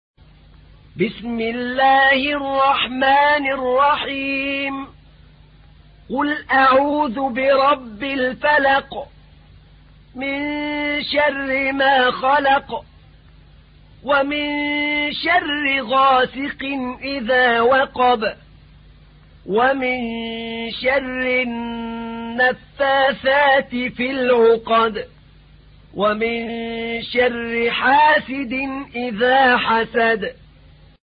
تحميل : 113. سورة الفلق / القارئ أحمد نعينع / القرآن الكريم / موقع يا حسين